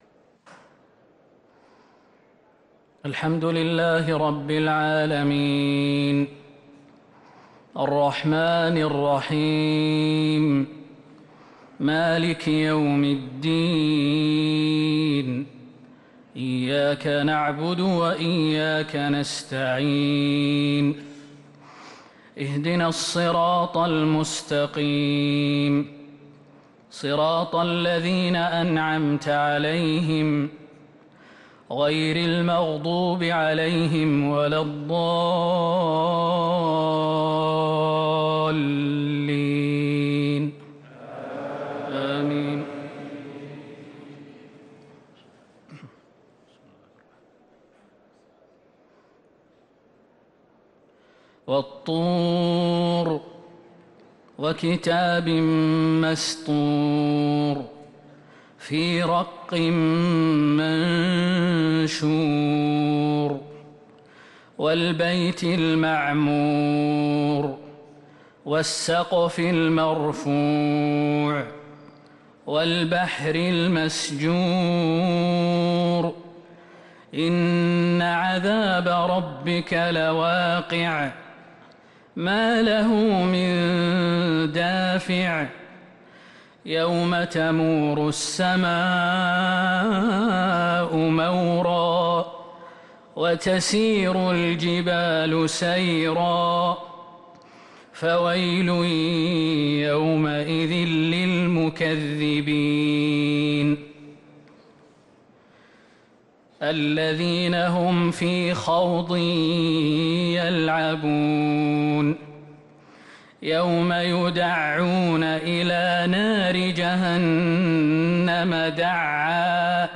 صلاة العشاء للقارئ خالد المهنا 26 شوال 1444 هـ
تِلَاوَات الْحَرَمَيْن .